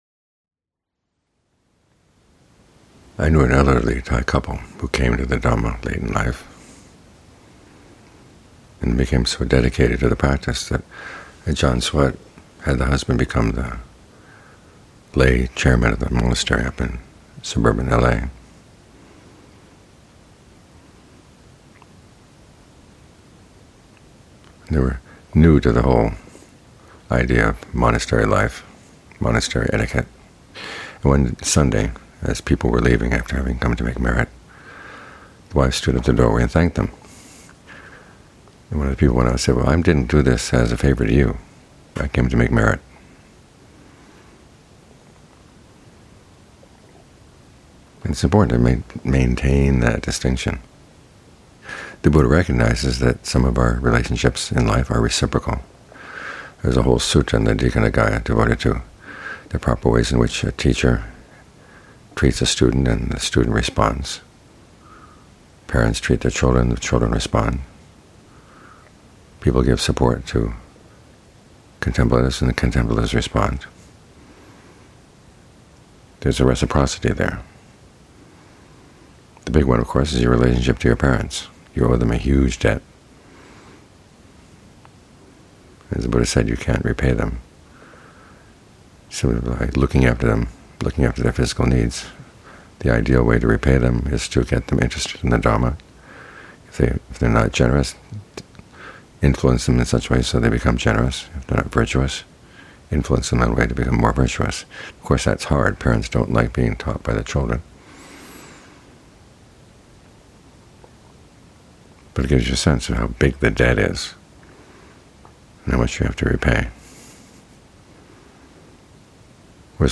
evening talks